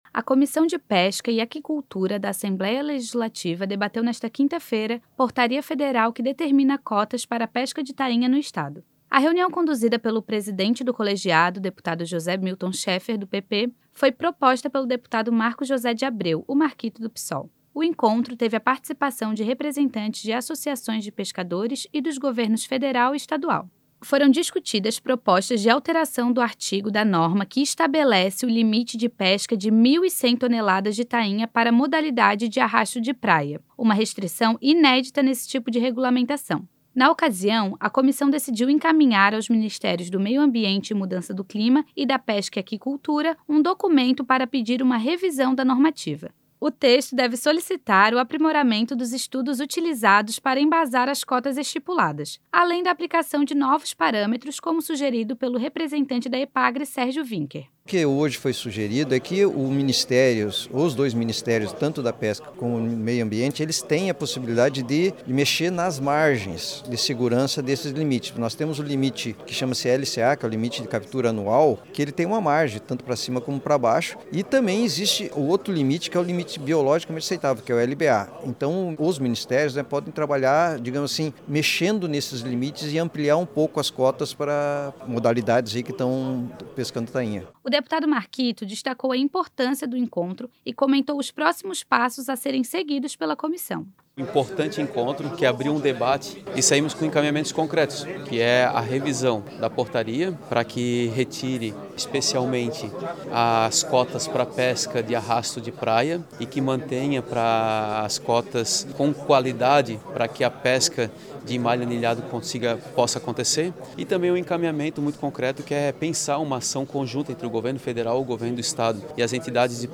Entrevistas com:
-deputado Marcos José de Abreu - Marquito (Psol);
-Tiago Frigo, secretário de Pesca e Aquicultura do Estado.